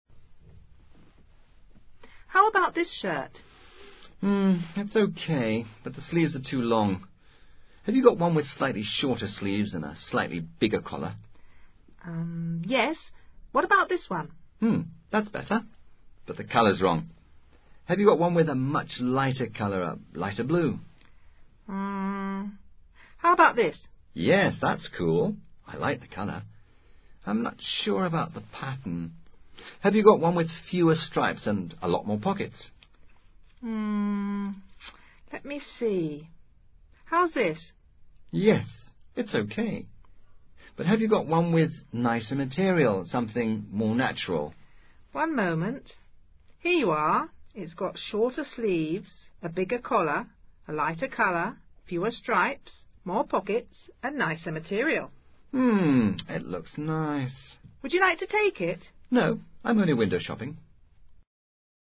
Sencillo diálogo que recrea una escena entre un vendedor y un posible comprador.